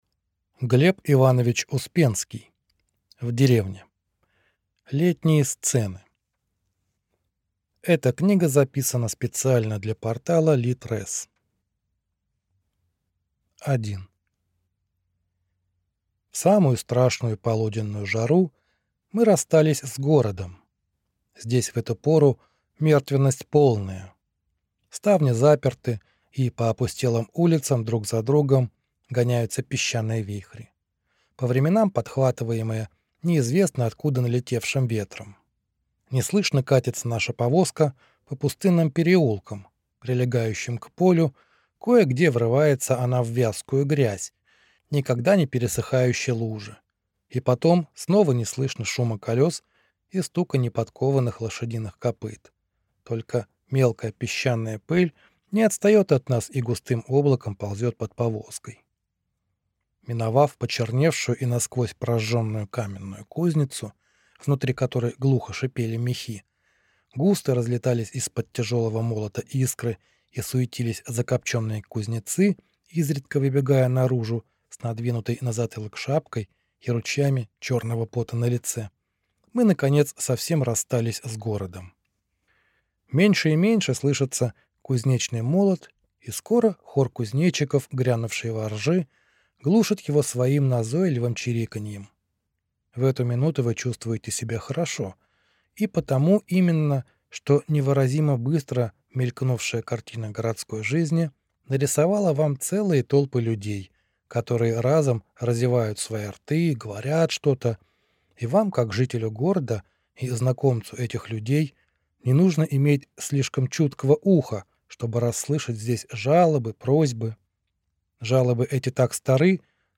Аудиокнига В деревне | Библиотека аудиокниг